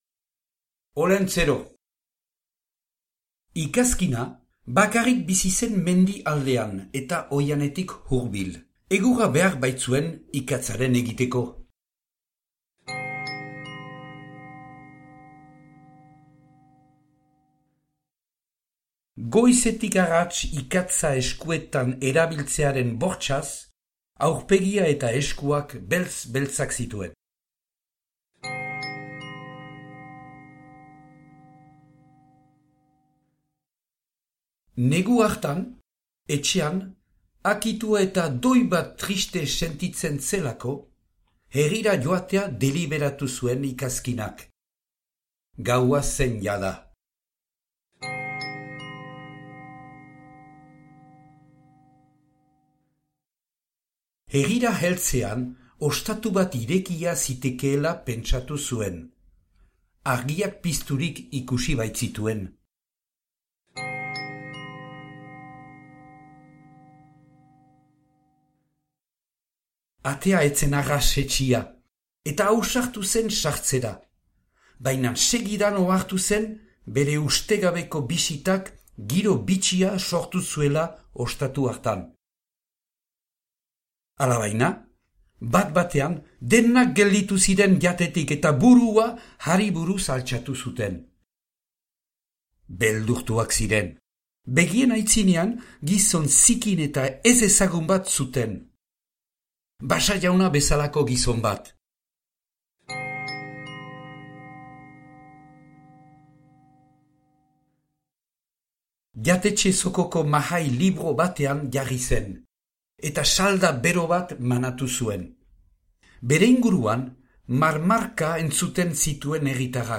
Olentzero - ipuina entzungai - batuaz